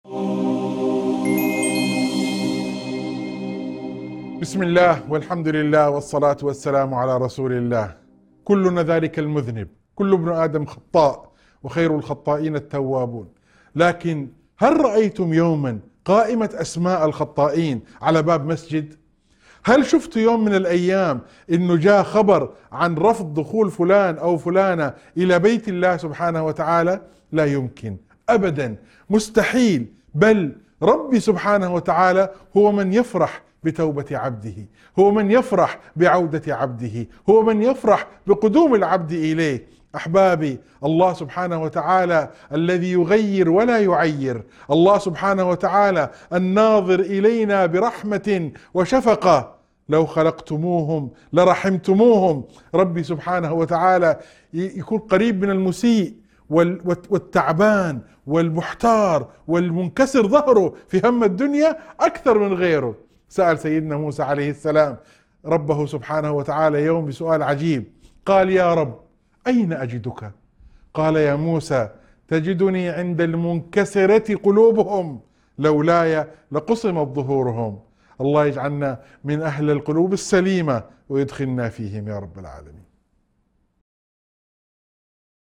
موعظة مؤثرة تؤكد على سعة رحمة الله تعالى وفرحه بتوبة عباده، مهما بلغت أخطاؤهم. يسلط النص الضوء على أن بيوت الله مفتوحة للجميع، وأن الله هو من يتقبل ويفرح بعودة المذنب التائب، مع الاستشهاد بقصة موسى عليه السلام.